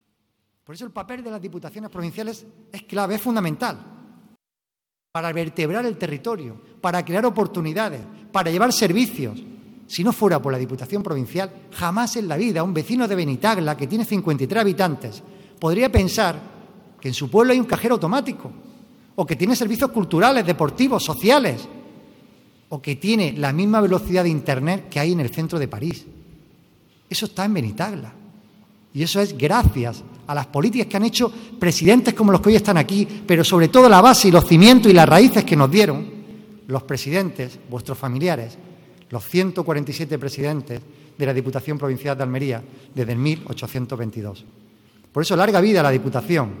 Esta tarde, en el Patio de Luces de la institución, ha tenido lugar la puesta de largo de esta obra ante un aforo repleto que no ha querido dejar pasar la oportunidad de conocer la historia que hay detrás de las 148 personas que han presidido la Diputación desde 1822.